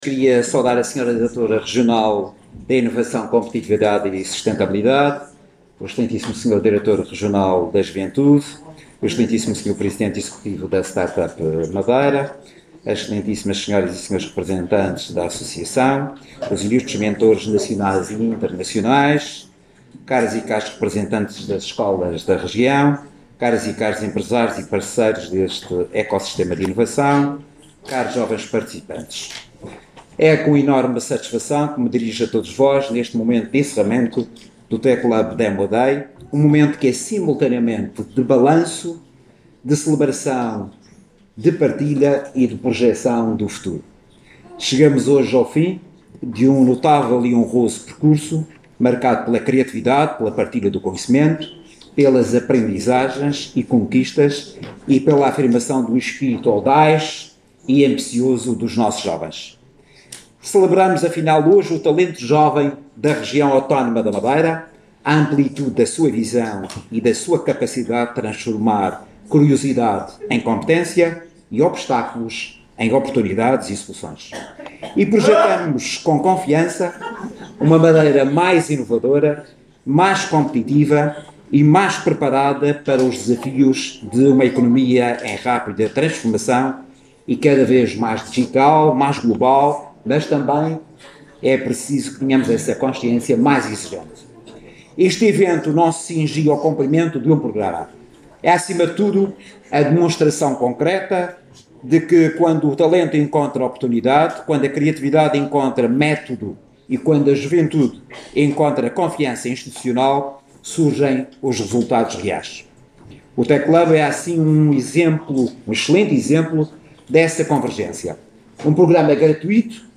As declarações foram registadas no Auditório da Reitoria da Universidade da Madeira, que acolheu, esta quinta-feira, à tarde, o Tech Lab Demo Day – Jovens Empreendedores Madeirenses, uma iniciativa organizada pela Direção Regional de Competitividade, Inovação e Sustentabilidade (DRCIS), em parceria com a Associação Madeira Friends, juntando jovens criadores madeirenses, mentores, professores e representantes do ecossistema tecnológico regional.